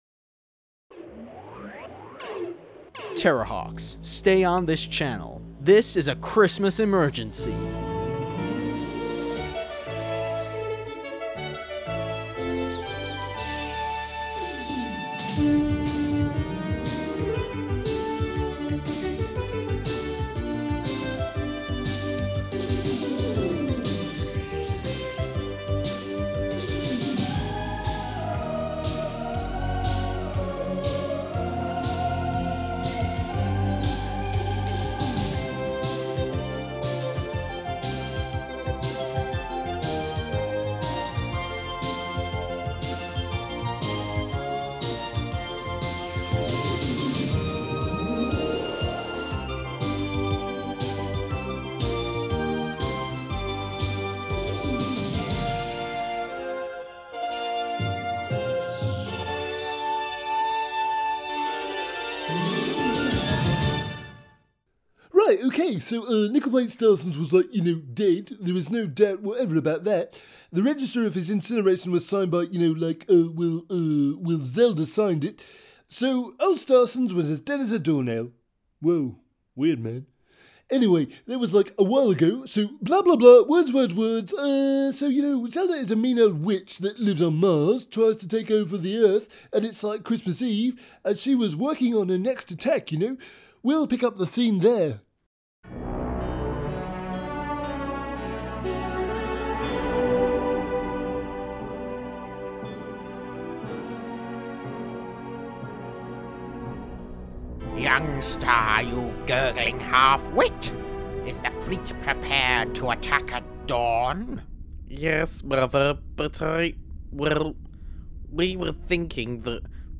Have you ever thought that there might be a likeness between the classic literary figure of Ebenezer Scrooge, and evil alien android Zelda from Terrahawks? Well wonder no more as we bring you a specially written and performed Terrahawks Christmas special which puts a Terrahawks spin on the famous Dickens tale of A Christmas Carol.